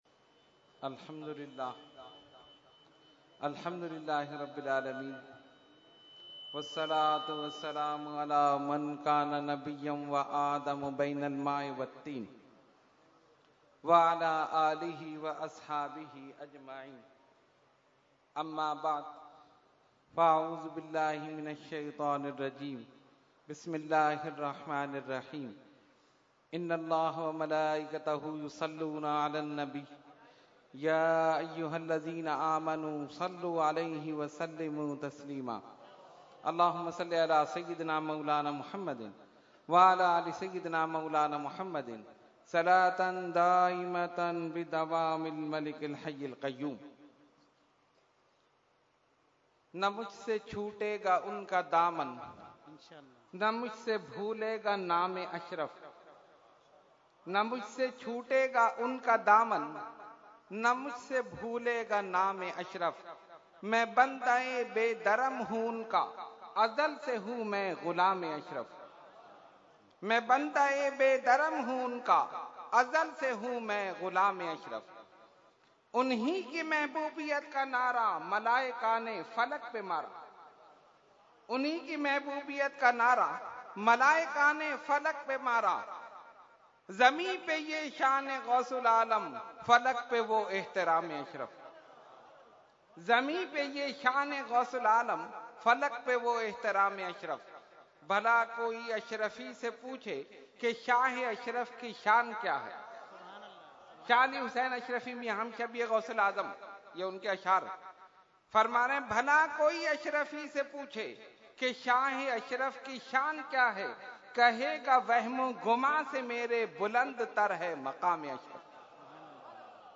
Category : Speech | Language : UrduEvent : Urs Makhdoome Samnani 2016